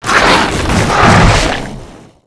击中后人倒地zth070511.wav
通用动作/01人物/02普通动作类/击中后人倒地zth070511.wav
• 声道 單聲道 (1ch)